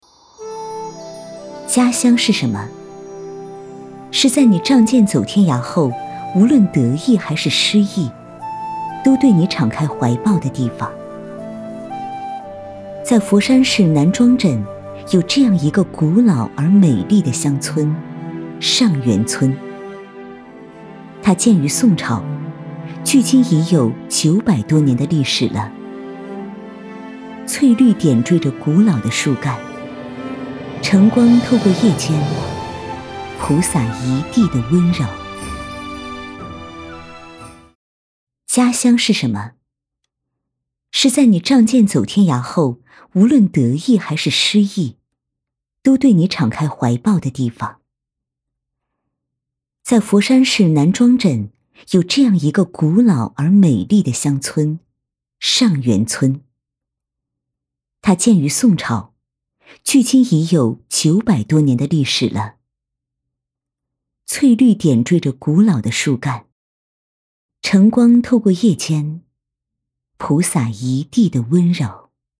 v385-【纪录片】上元村申遗感觉
女385温柔知性配音 v385
v385--纪录片-上元村申遗感觉.mp3